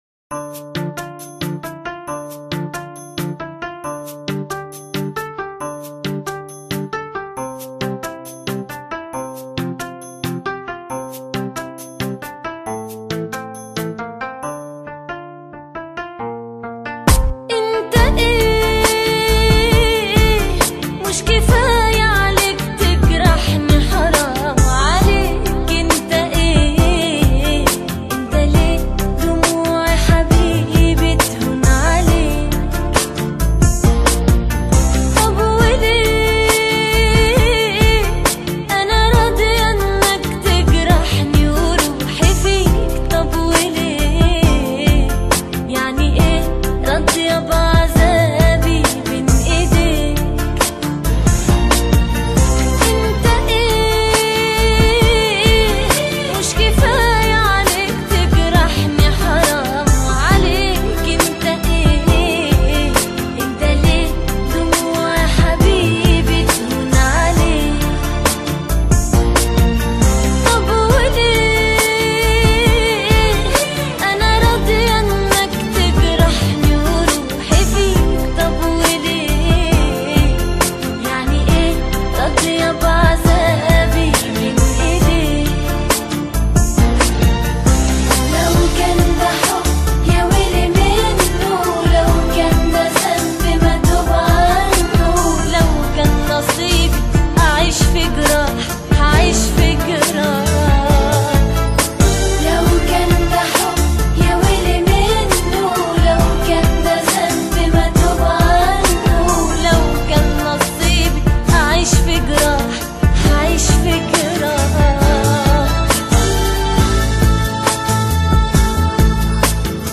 آهنگ عربی
آهنگ با صدای زن